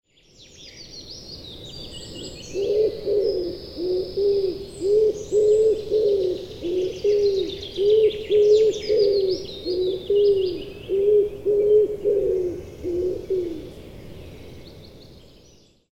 kaelustuvi.ogg